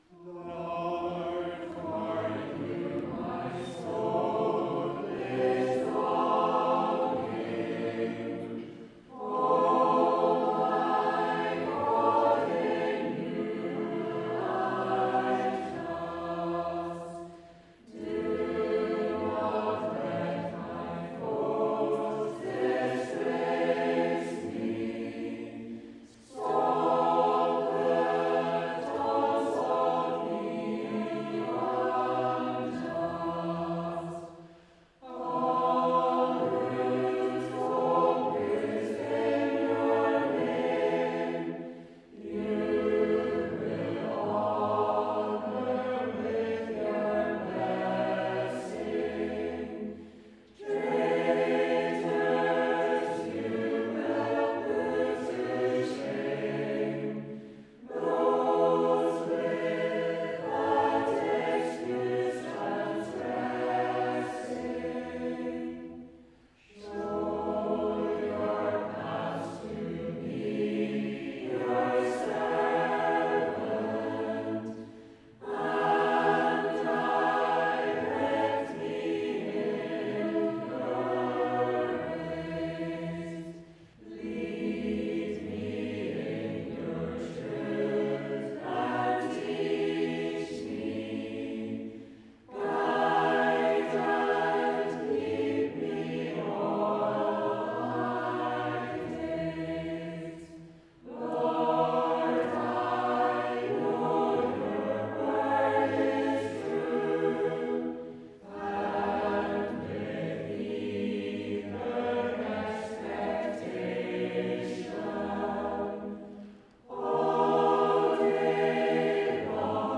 Congregational Psalm Singing Psalm 25 v 1-7 Evening Worship